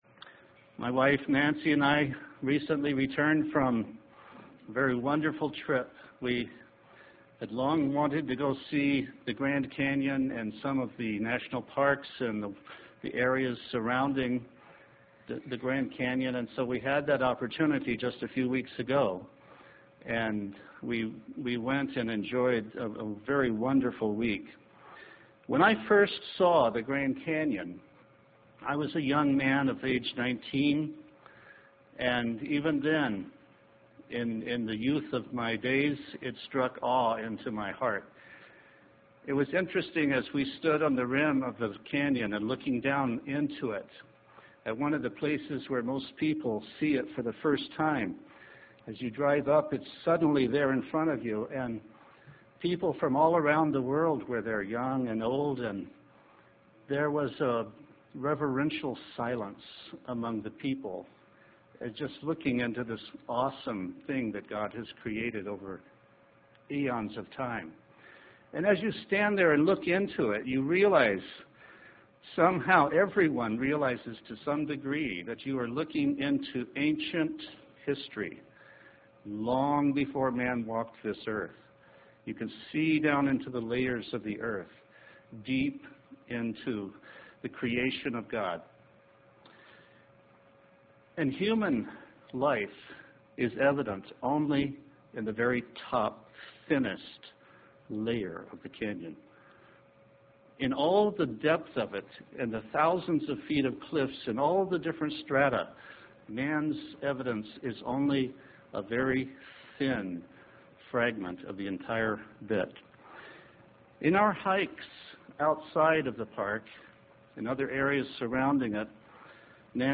Given in Spokane, WA
UCG Sermon Studying the bible?